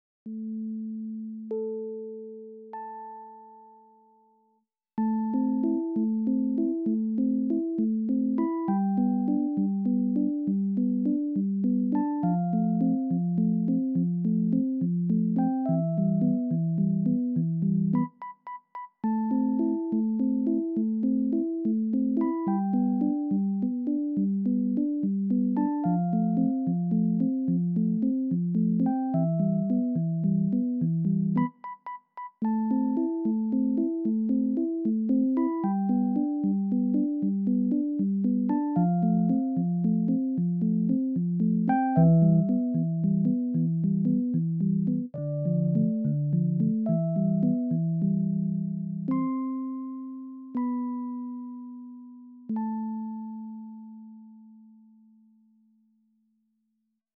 Musiche – OST, ho giocato con l’Ipad e ri-registrato la sigla oltre ad un paio dei pezzi per questo episodio che si chiamano solo